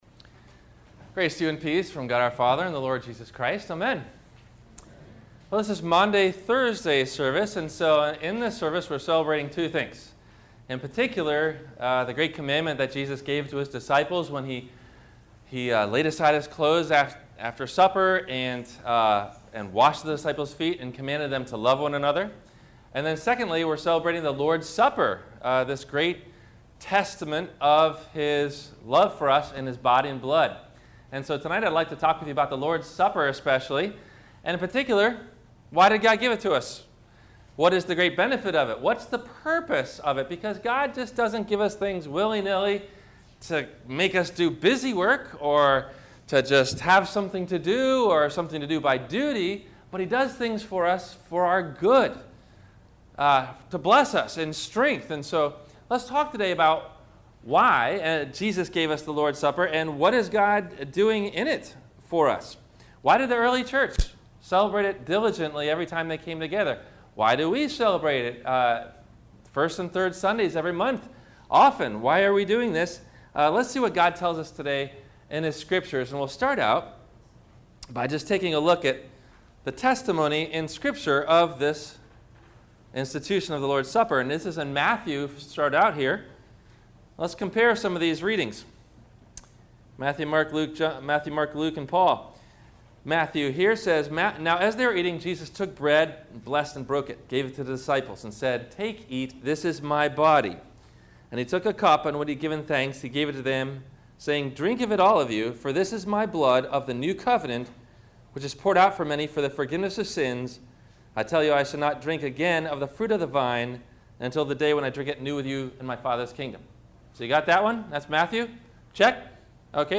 - Maundy Thursday - Sermon - April 17 2014 - Christ Lutheran Cape Canaveral